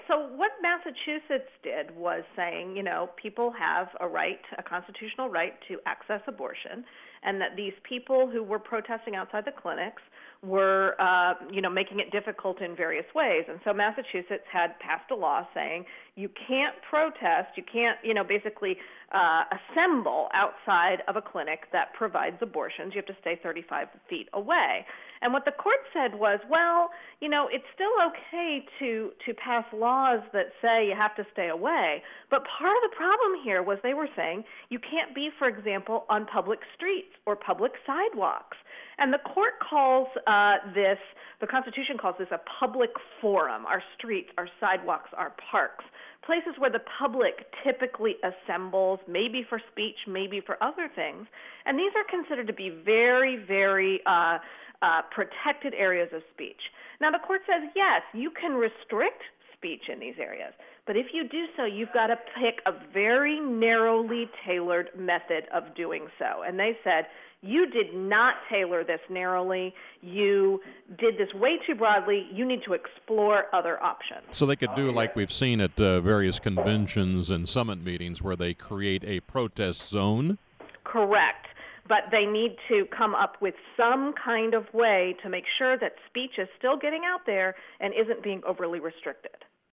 Recent Radio Commentary